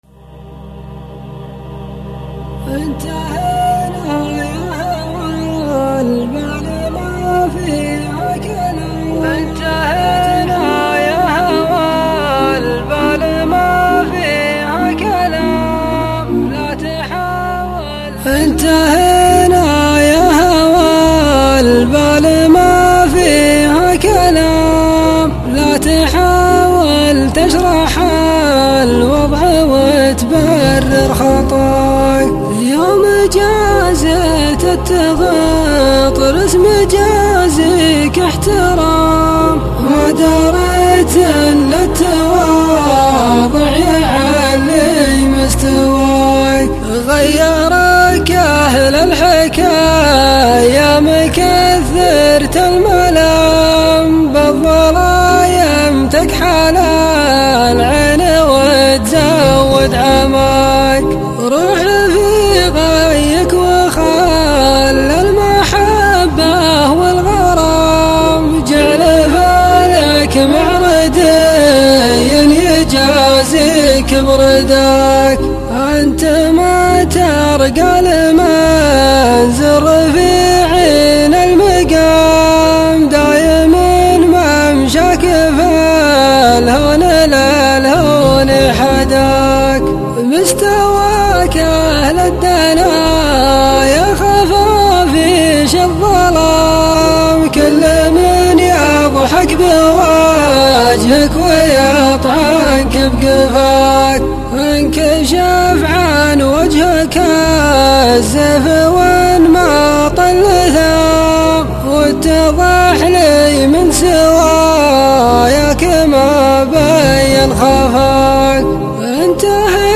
الشيلات -> شيلات منوعه 7